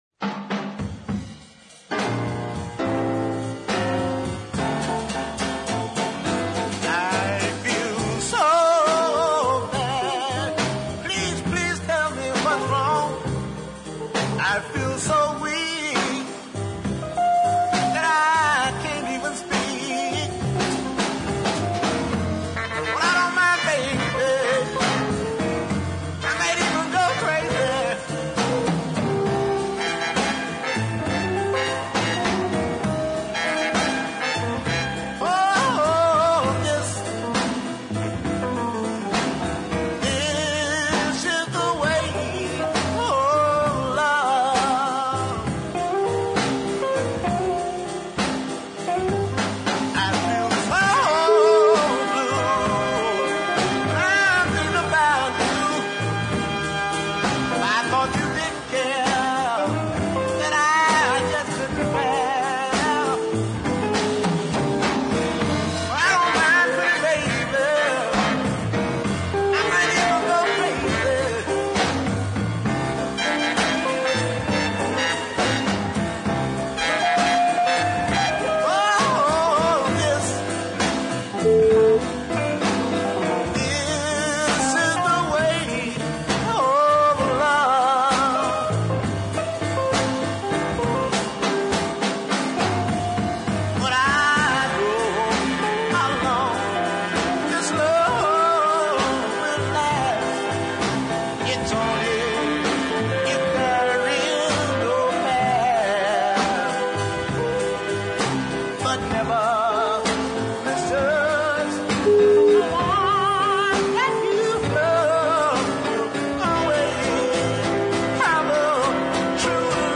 Great band support too.